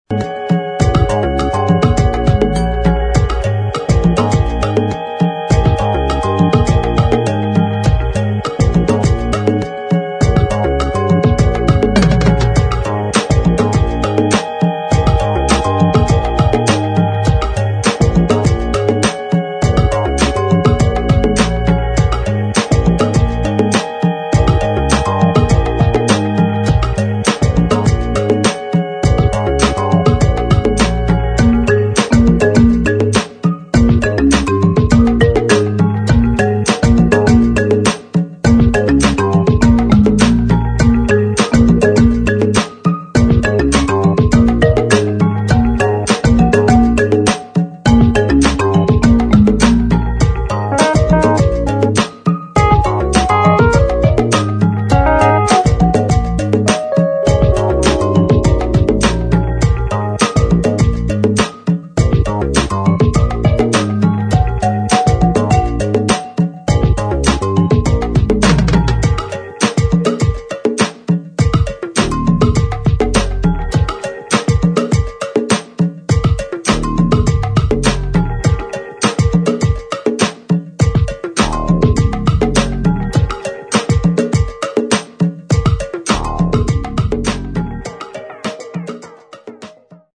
[ HOUSE / COSMIC ]